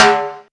prcTTE44020tom.wav